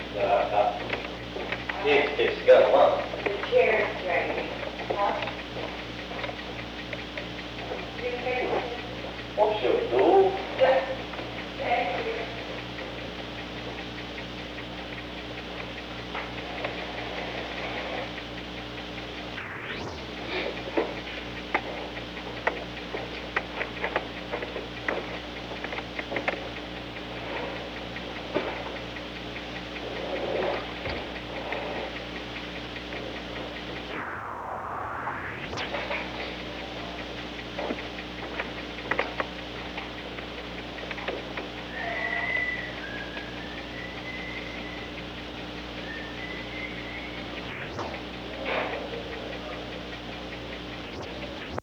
Recording Device: Old Executive Office Building
The Old Executive Office Building taping system captured this recording, which is known as Conversation 303-012 of the White House Tapes.
Chair
Whistling